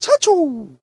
The President calling out his name, after switching leaders. This file was ripped directly from the ISO.